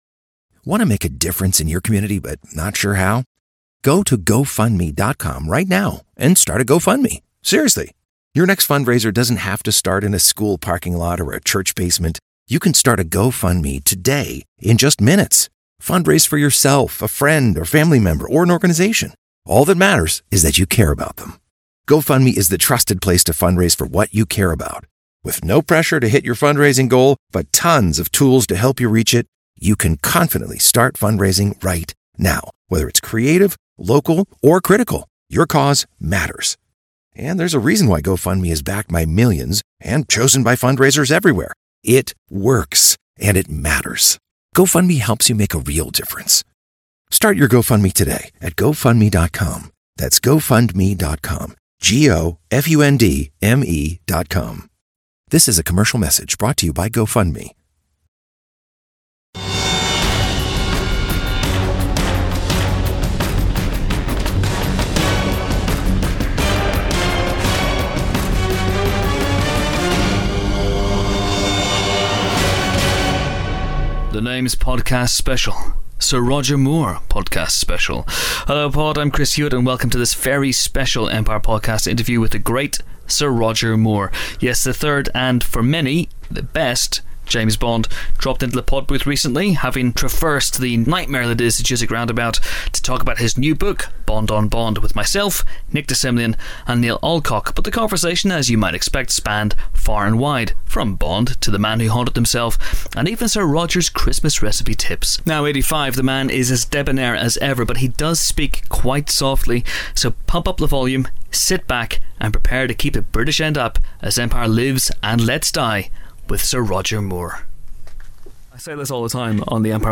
Sir Roger Moore Special The Empire Film Podcast Bauer Media Tv & Film, Film Reviews 4.6 • 2.7K Ratings 🗓 1 November 2012 ⏱ 40 minutes 🔗 Recording | iTunes | RSS 🧾 Download transcript Summary The one and only Sir Roger Moore stopped by the Empire Podcast studio this week in honour of his latest book, Bond On Bond. The result is this 40-minute special interview podcast, which covers everything from the third Bond's Christmas dinner tips to explaining the plot of Inception. A word of warning, however - though he's as debonair and funny as ever, the 85-year-old Sir Roger does speak rather softly, so do pay attention 007...